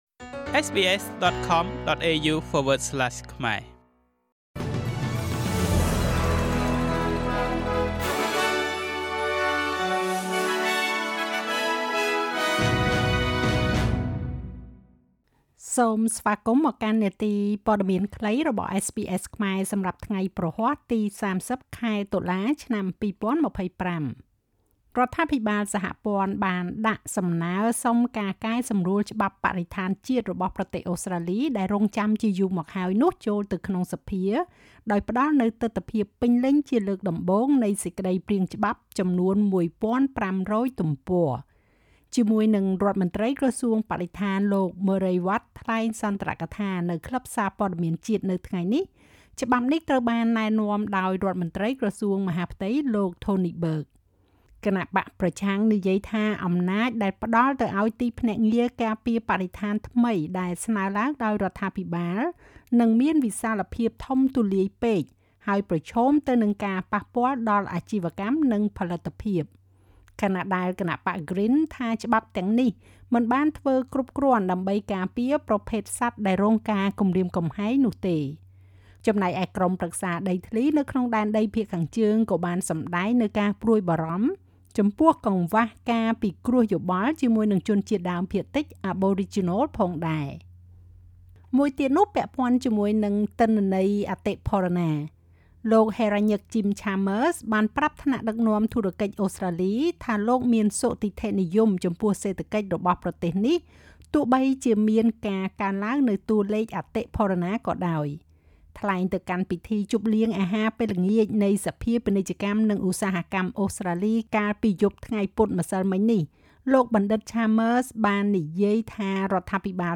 នាទីព័ត៌មានខ្លីរបស់SBSខ្មែរសម្រាប់ថ្ងៃព្រហស្បតិ៍ ទី៣០ ខែតុលា ឆ្នាំ២០២៥